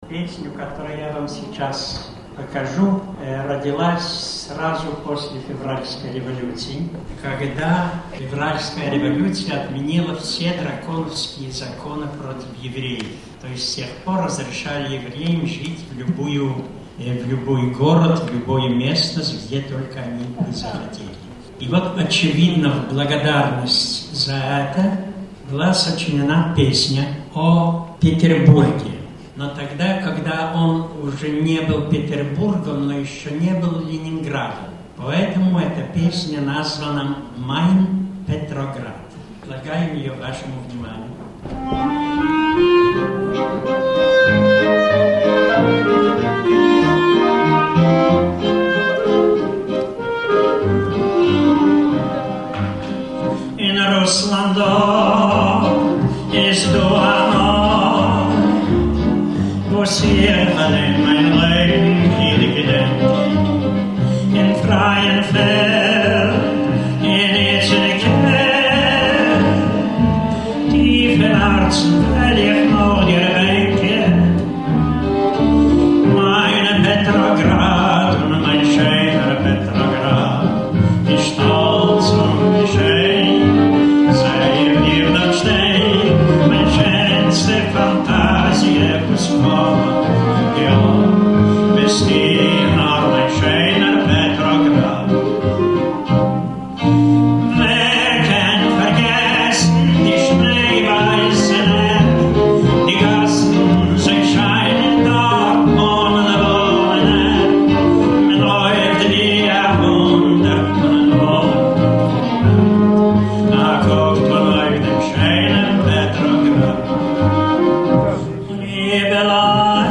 Четвертый международный фестиваль еврейской музыки
фестиваль клезмерской музыки